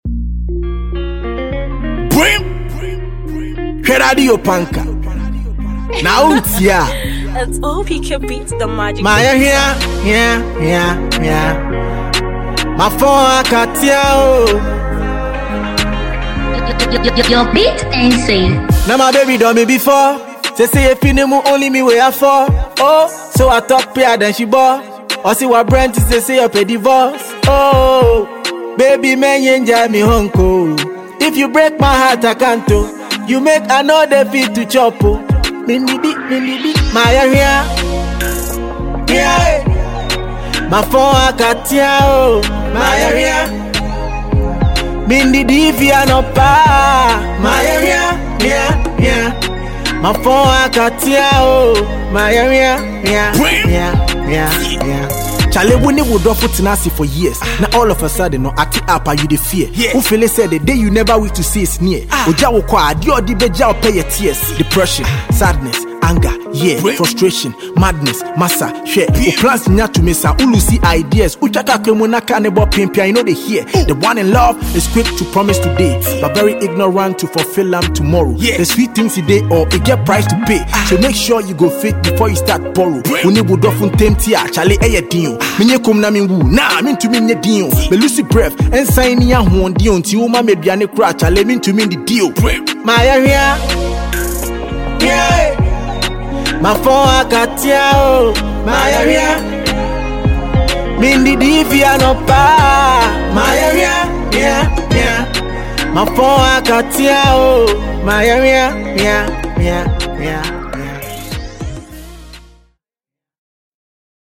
catchy single
Afrobeat